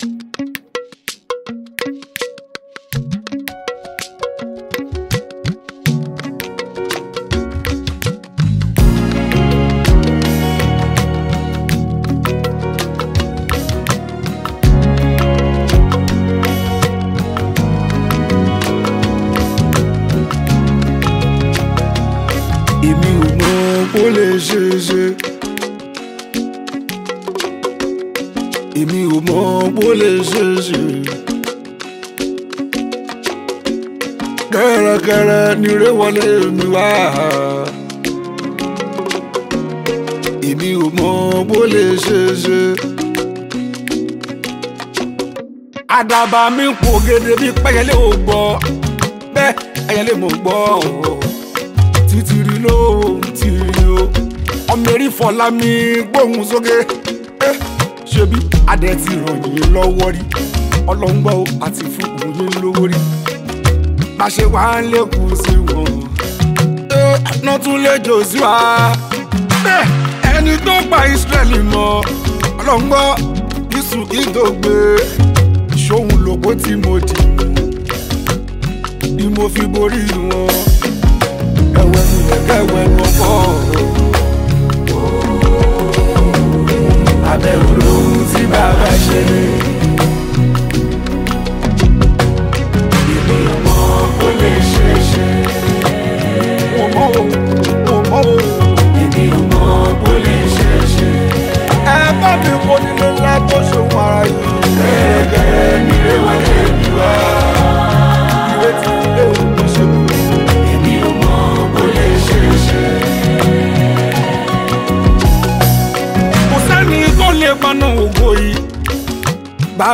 Gospel
especially people with so much love for Yoruba Fuji Music.